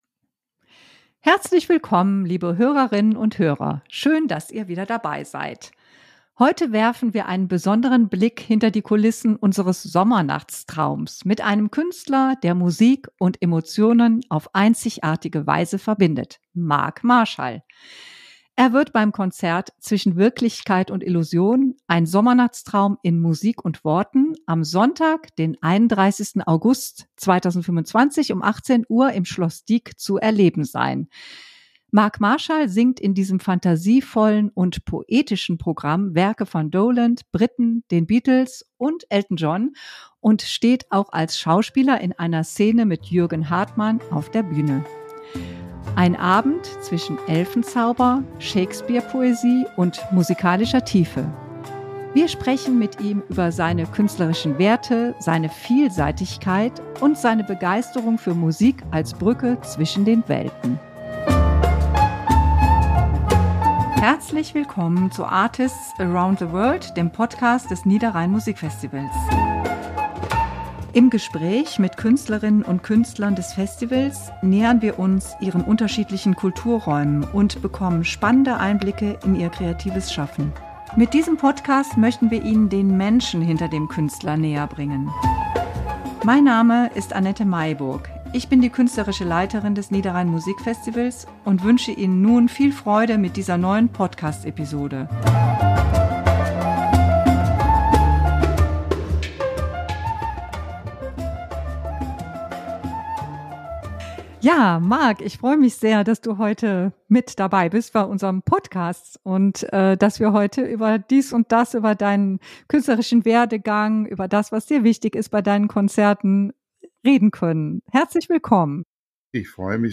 018 Dialog auf Augenhöhe | Interview mit Marc Marshall – gefeierter Bariton, Entertainer & Meister der leisen Töne ~ Artists around the world - Der Podcast zum Niederrhein Musikfestival Podcast